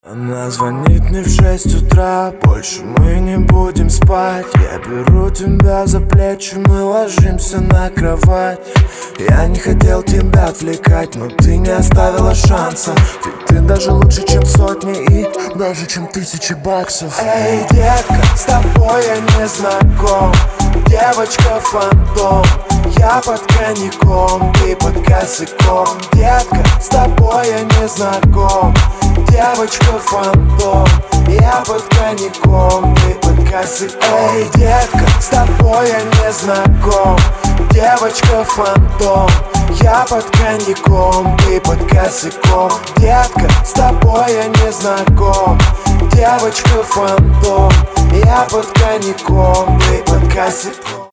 • Качество: 320, Stereo
Хип-хоп
dancehall
dance
club